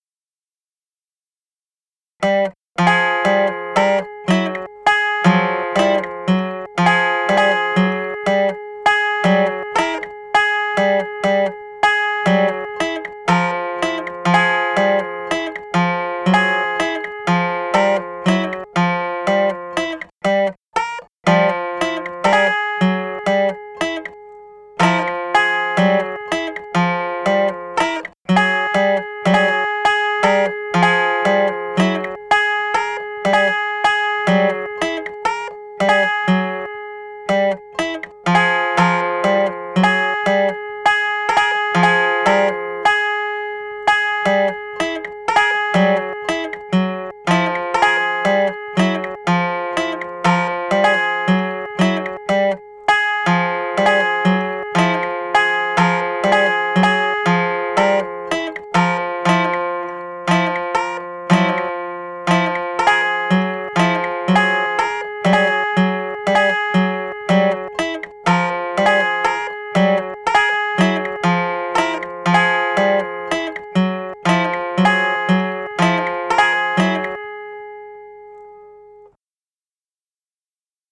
Mit Gitarrensamples: